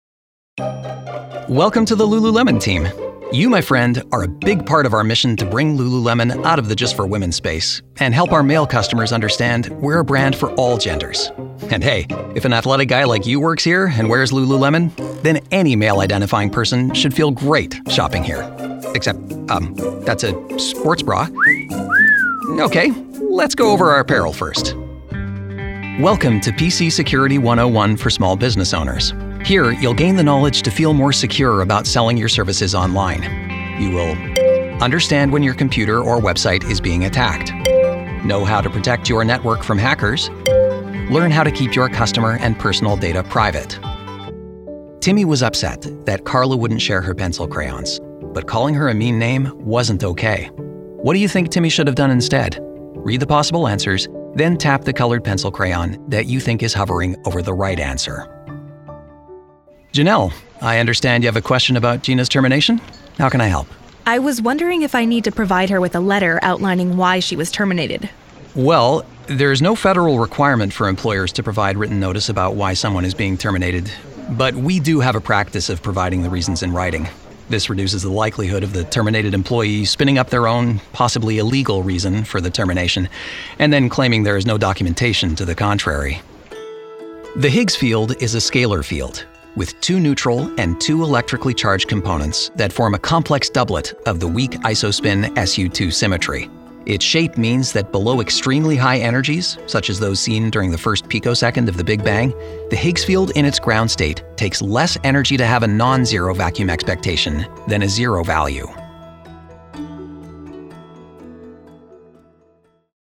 Male
American English (Native) , Canadian English (Native) , French Canadian
Approachable, Assured, Authoritative, Confident, Conversational, Corporate, Deep, Energetic, Engaging, Friendly, Funny, Gravitas, Natural, Posh, Reassuring, Sarcastic, Smooth, Soft, Upbeat, Versatile, Warm, Witty
dry studio read.mp3
Microphone: Sennheiser 416